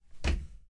敲击声
描述：用岩石敲门的东西